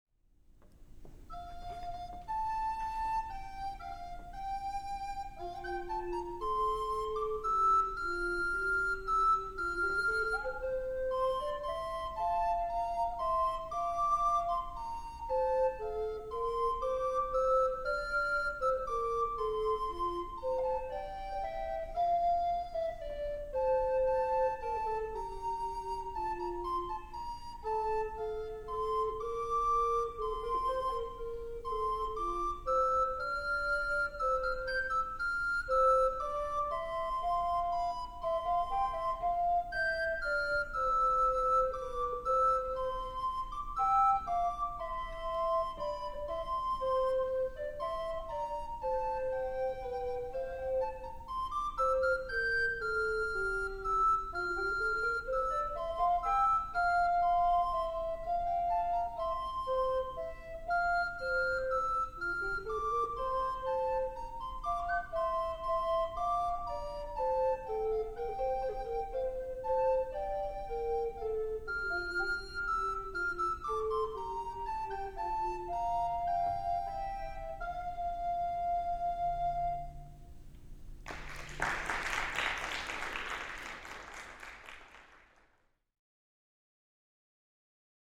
Audio examples from a recent recital:
"Conuerte Nos," a sixteenth-century duet for soprano and tenor recorders composed by Laurentus Lemin.